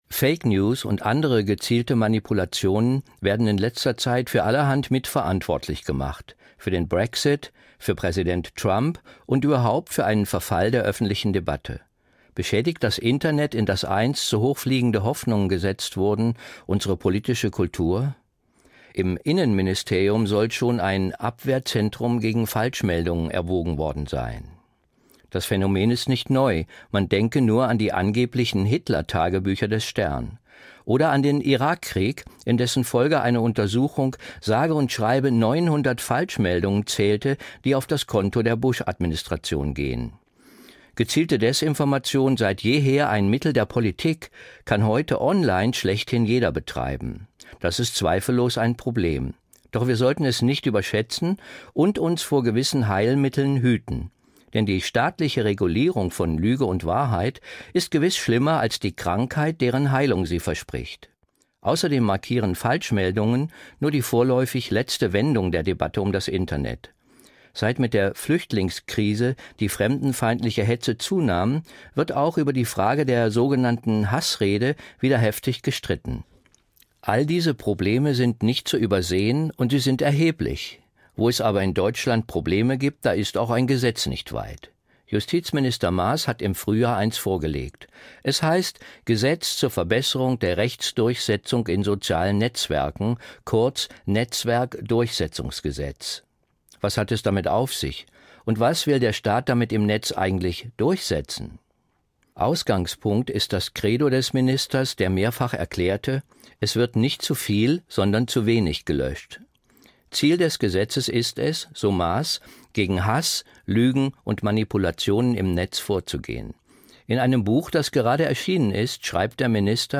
Essay Gedanken zur Zeit, NDR Kultur, 25.